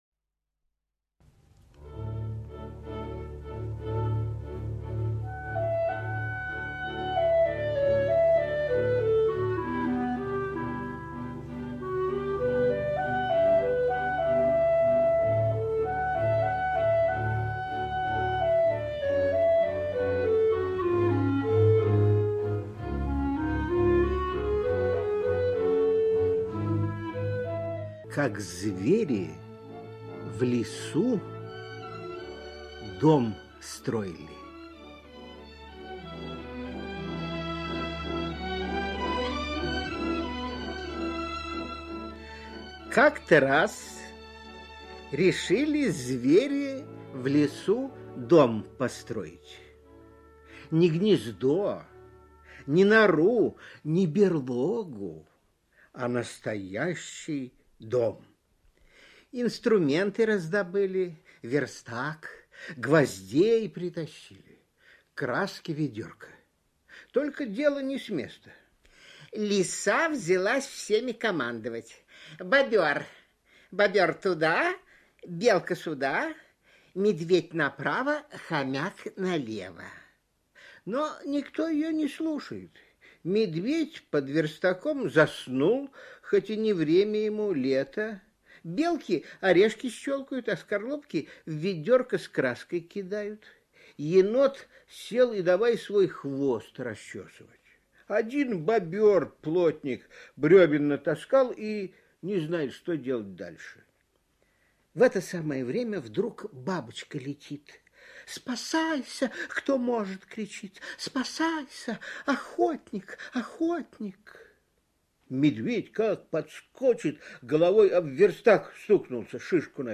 Как звери в лесу дом строили – Заходер Б.В. (аудиоверсия)
Аудиокнига в разделах